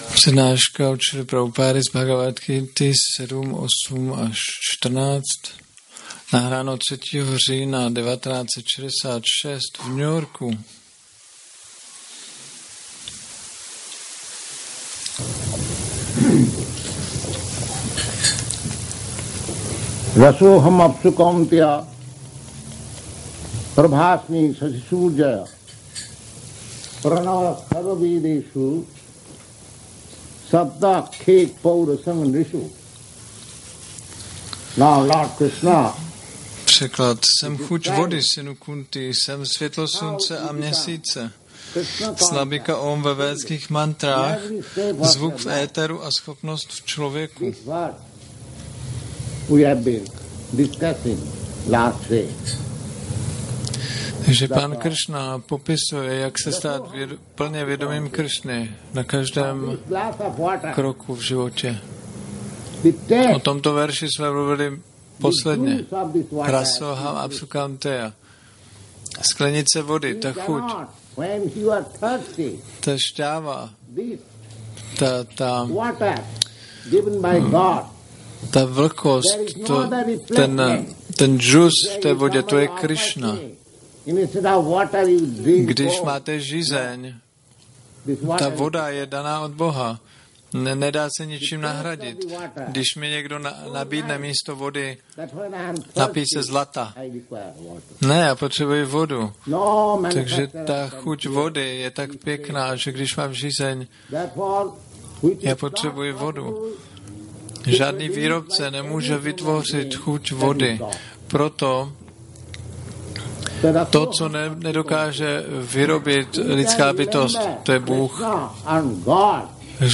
1966-09-09-ACPP Šríla Prabhupáda – Přednáška BG-7.8-14 New York